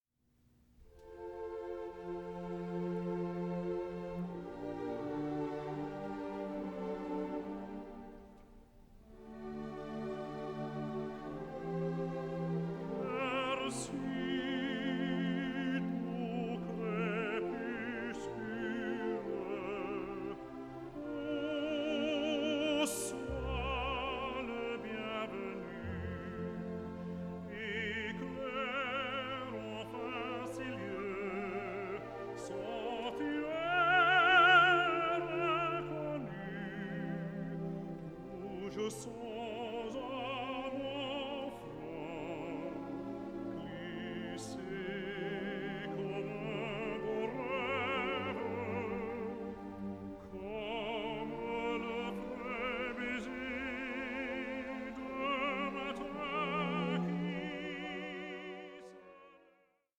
Légende dramatique en quatre parties
soprano
tenor
bass